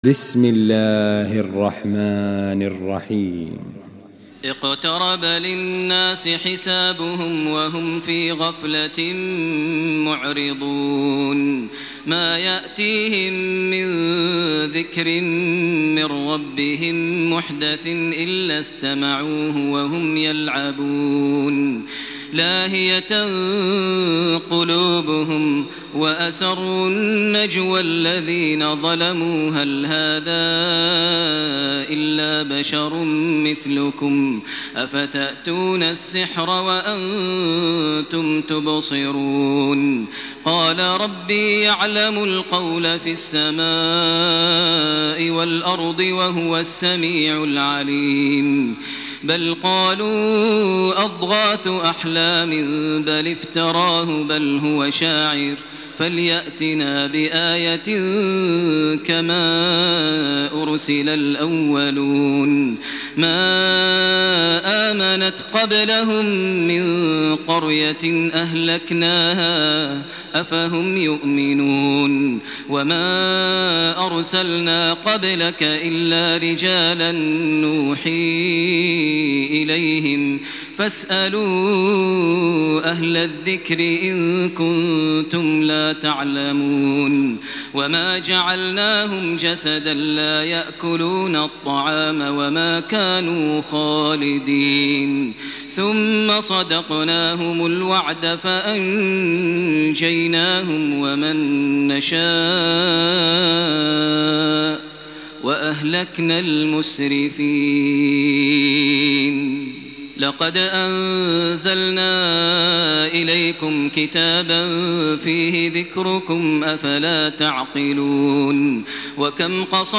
تراويح الليلة السابعة عشر رمضان 1428هـ سورة الأنبياء كاملة Taraweeh 17 st night Ramadan 1428H from Surah Al-Anbiyaa > تراويح الحرم المكي عام 1428 🕋 > التراويح - تلاوات الحرمين